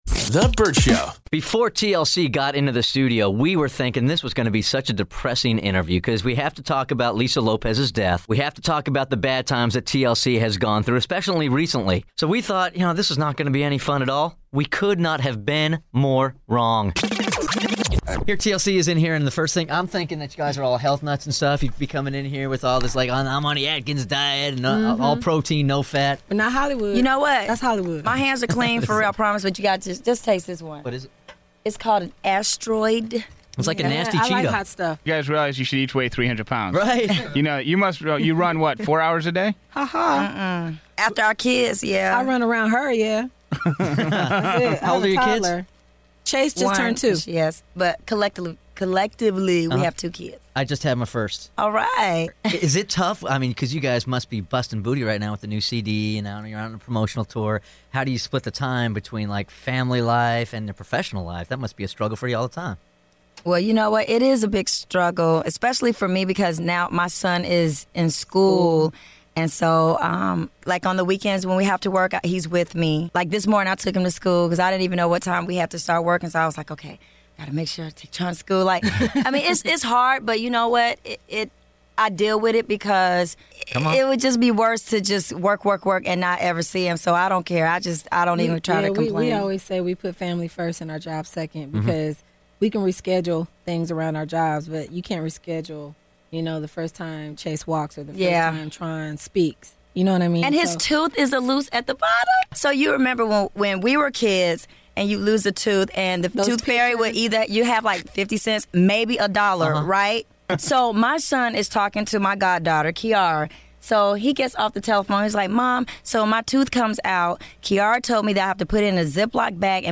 Vault: Interview With TLC